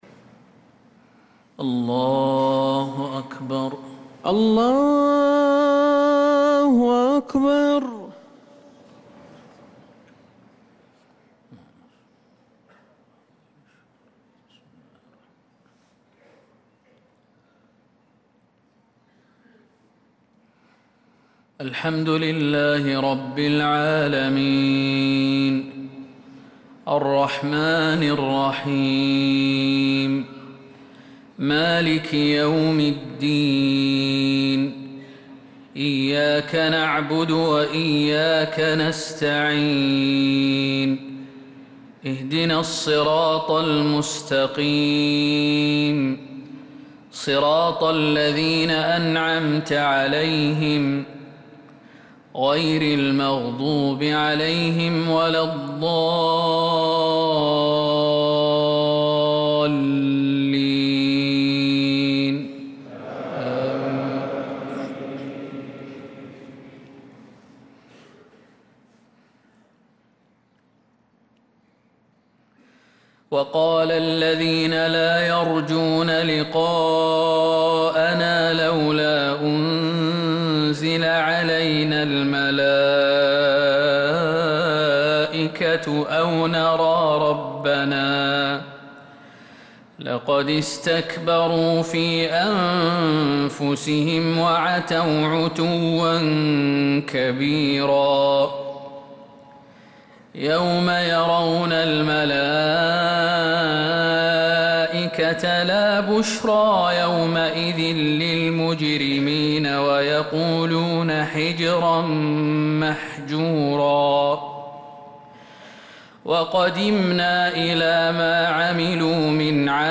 صلاة الفجر
تِلَاوَات الْحَرَمَيْن .